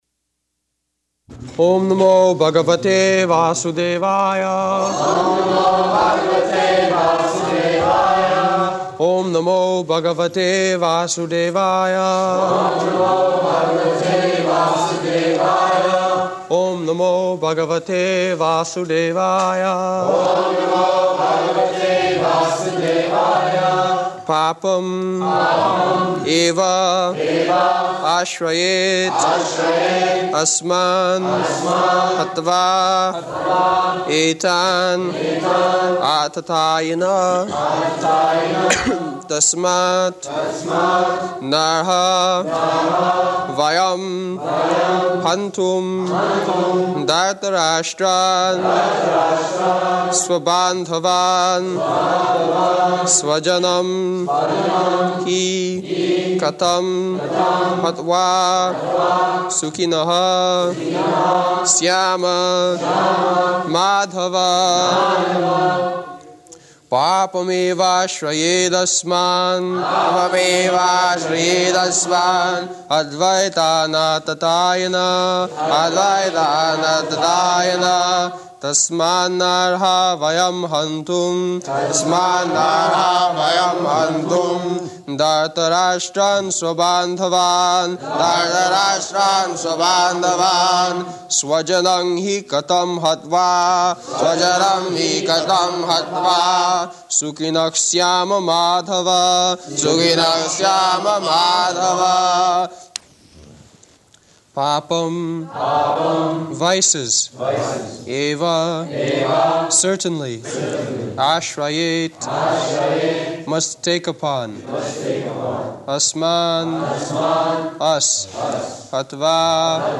-- Type: Bhagavad-gita Dated: July 26th 1973 Location: London Audio file
[devotees repeat] [leads chanting of verse]